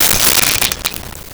Camera Flash Bulb Crackle 02
Camera Flash Bulb Crackle 02.wav